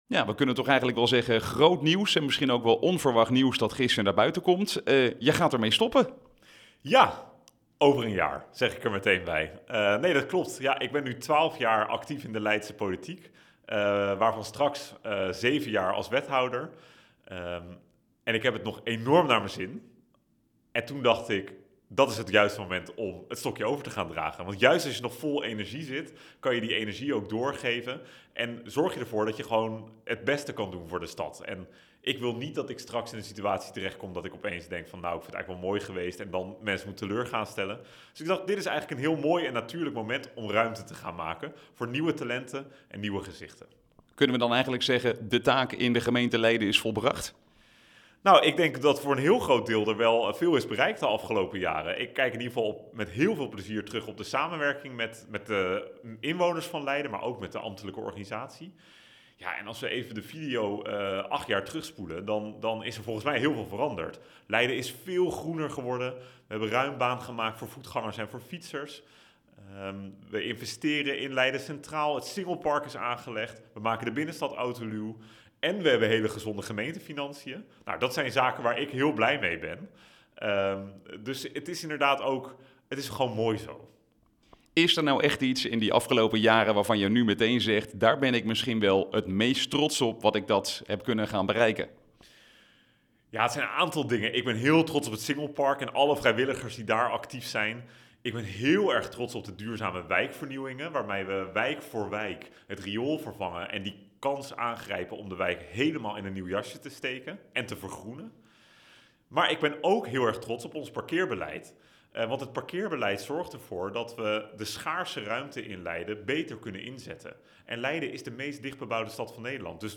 Ashley North in gesprek